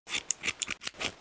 haircut4.wav